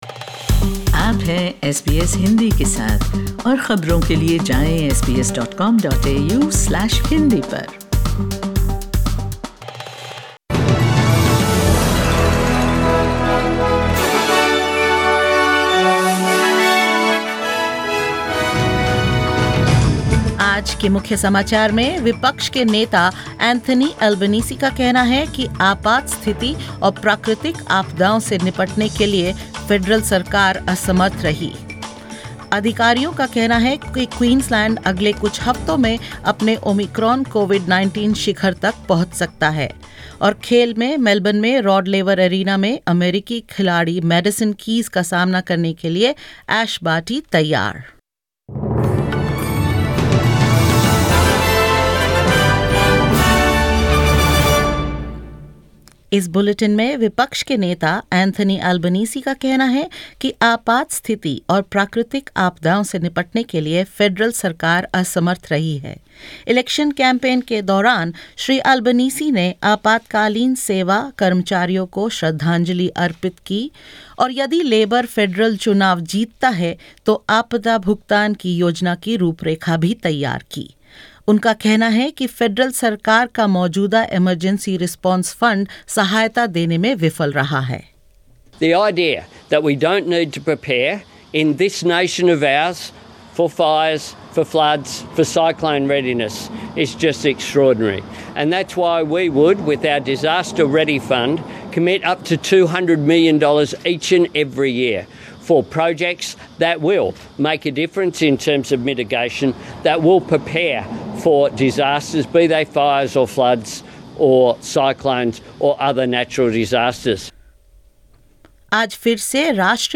In this latest SBS Hindi bulletin: Opposition leader Anthony Albanese accuses the federal government of not doing enough to prepare for or respond to emergencies and natural disasters; Queensland health officials predict that the state could reach its Omicron COVID-19 peak over the next few weeks and more.